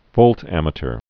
(vōltămə-tər)